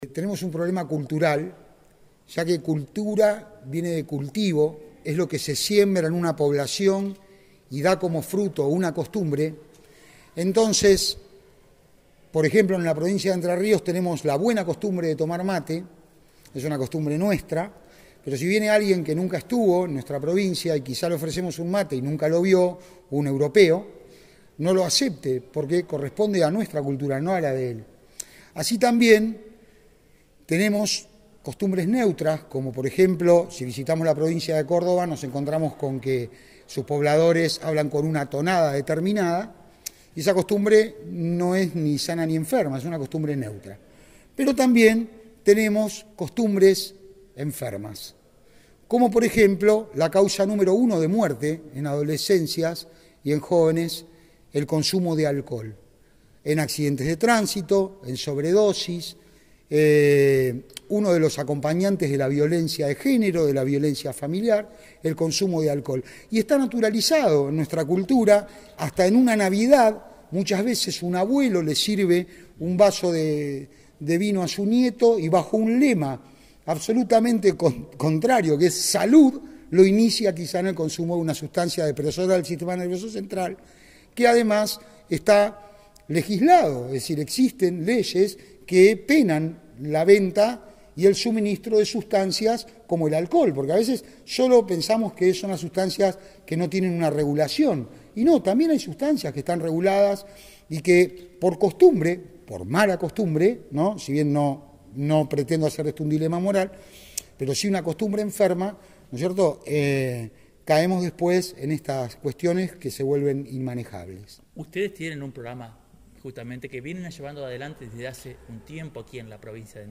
Charla sobre adicciones